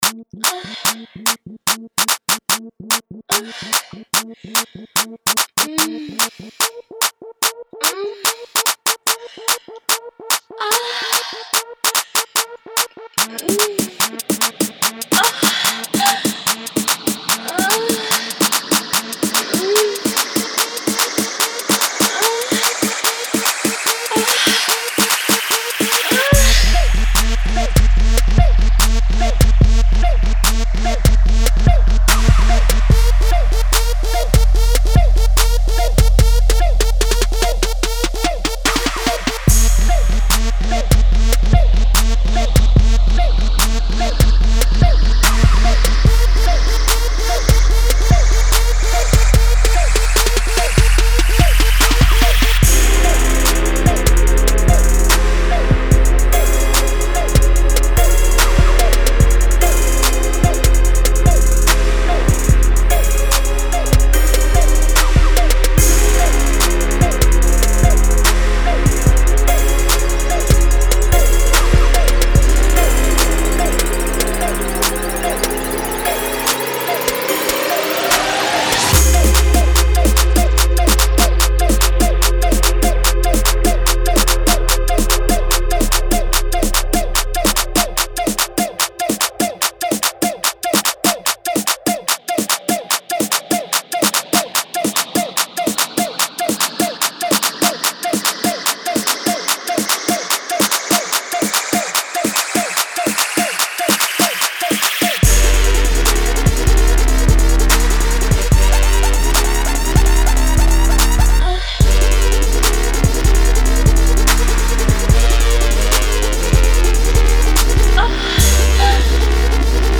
Trap